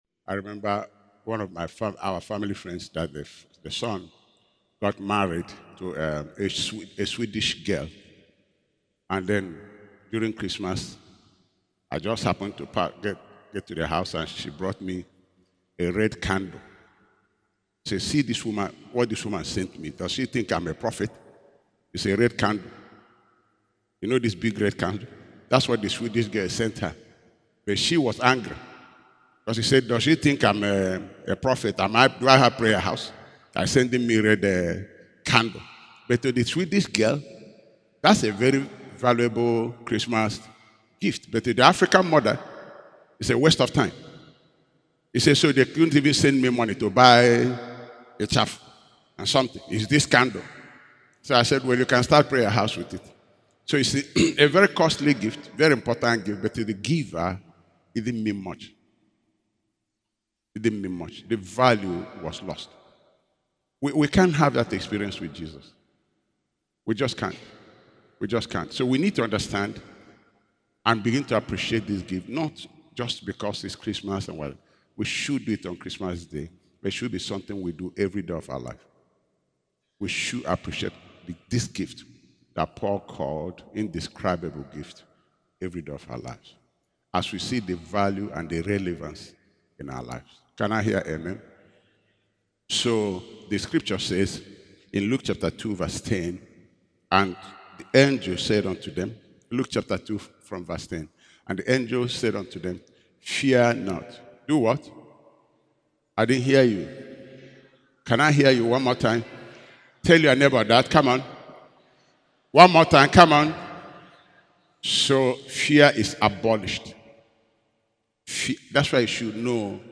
Christmas Day Service